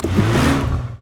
car-engine-load-reverse-1.ogg